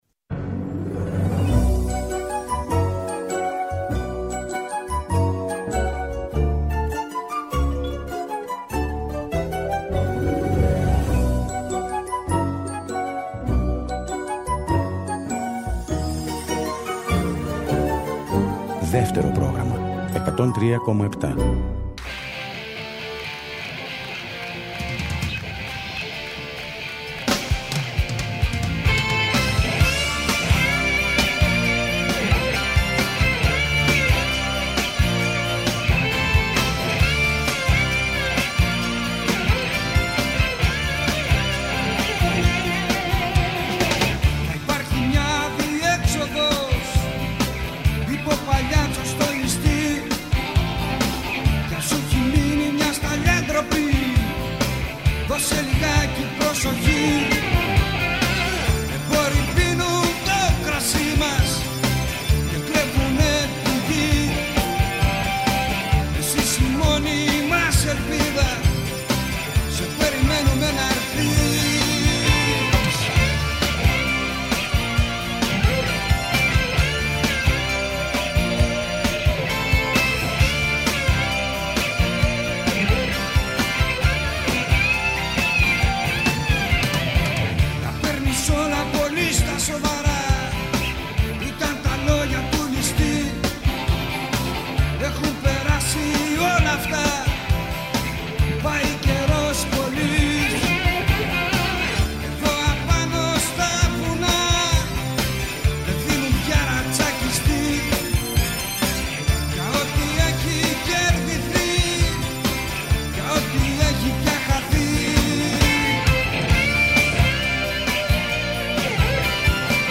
“Ροκ συναναστροφές” με το …”ροκ” όχι μόνο ως μουσική φόρμα, αλλά περισσότερο ως στάση ζωής. Αγαπημένοι δημιουργοί και ερμηνευτές αλλά και νέες προτάσεις, αφιερώματα και συνεντεύξεις, ο κινηματογράφος, οι μουσικές και τα τραγούδια του.